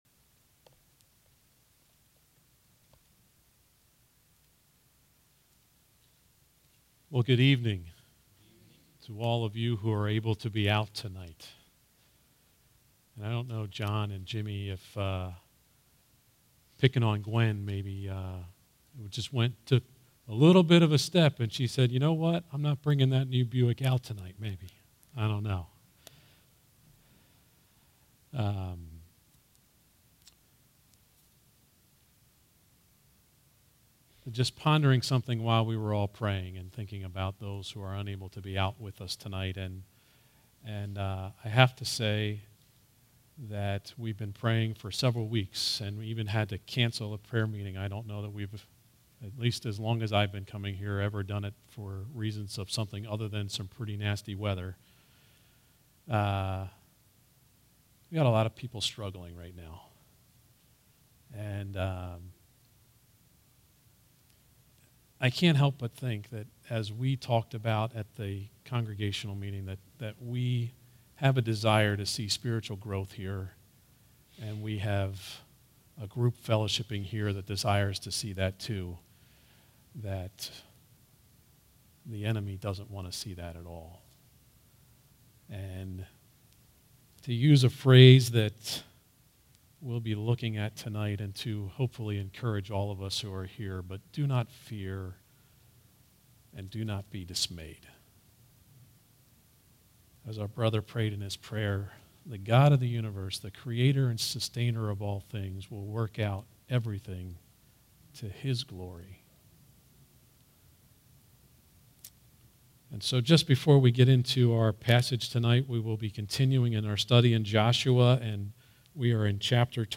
All Sermons Joshua 23:1-16 | Joshua’s Farewell Address to the Leaders of Israel and Passing the Torch.